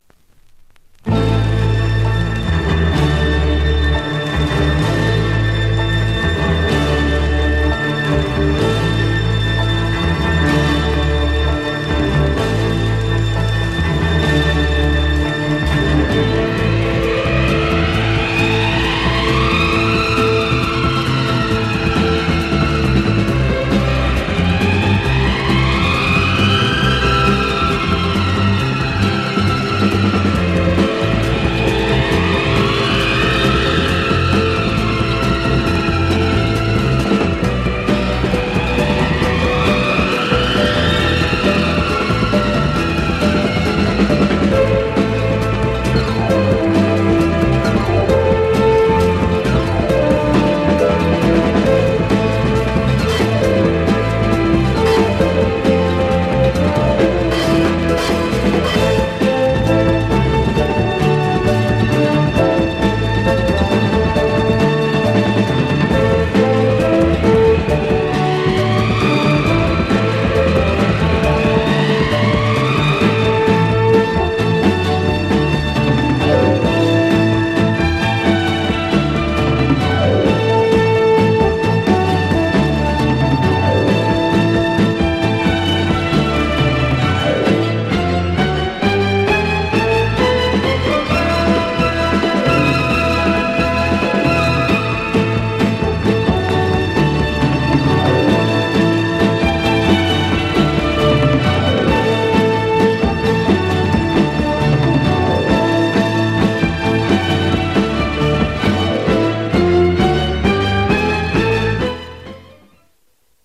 French library easy groove
Incredible strange soundtrack music on both sides.